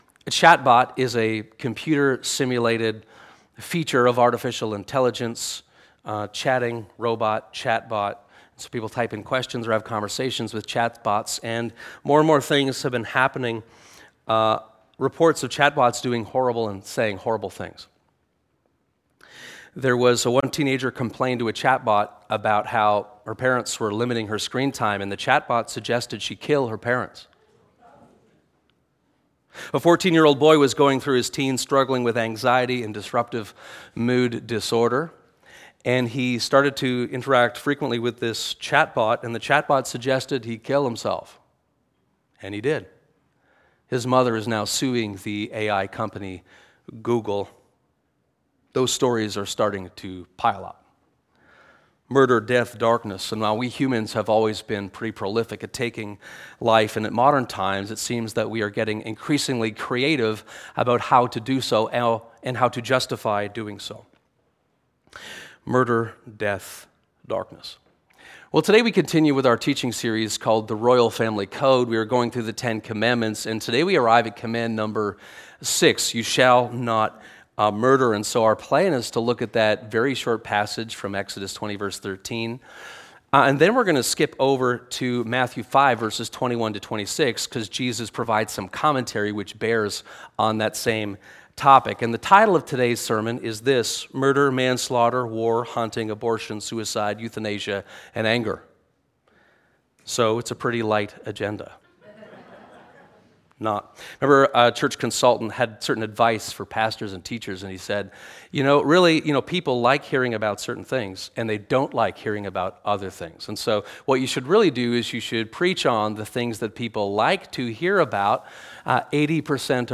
This sermon explores the sixth command, “You shall not murder,” alongside Jesus’ instruction in Matthew 5:21-26.